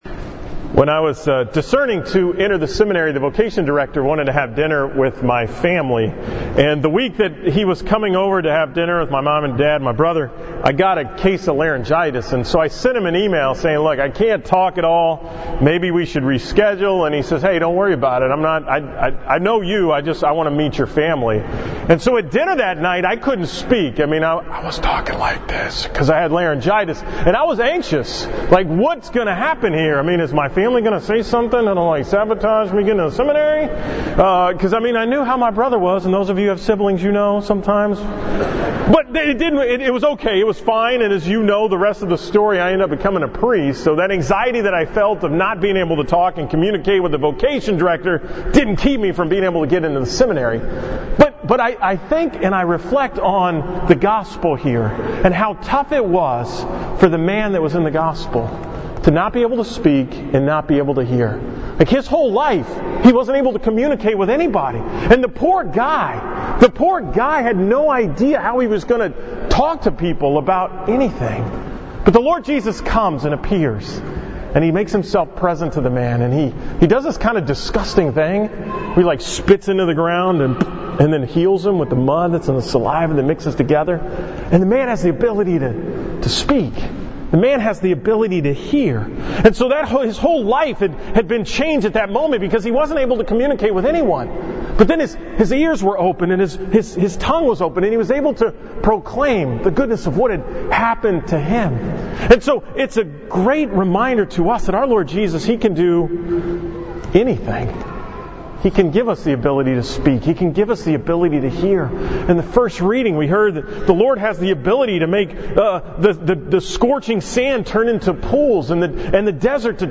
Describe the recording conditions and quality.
From the 23rd Sunday of Ordinary Time at St. Martha's on September 9th.